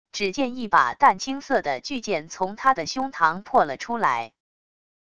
只见一把淡青色的巨剑从他的胸膛破了出来wav音频生成系统WAV Audio Player